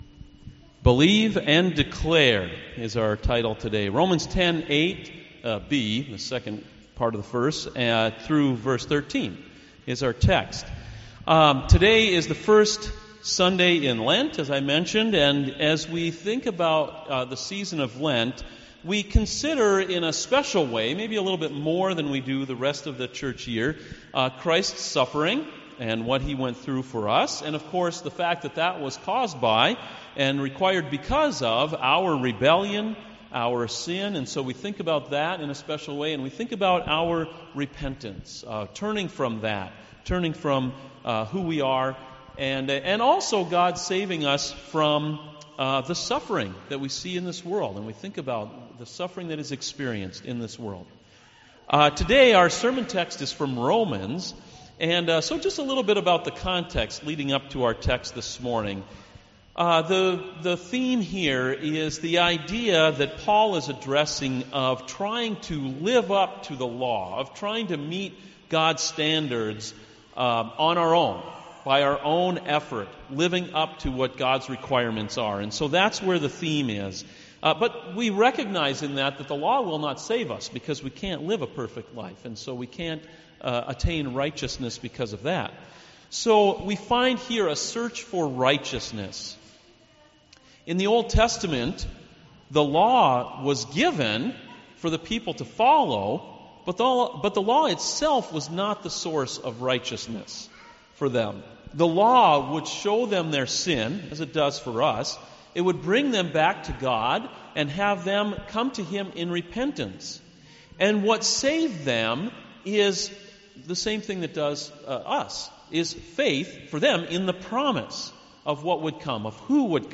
Sermons Believe and Declare (Romans 10:8b-13)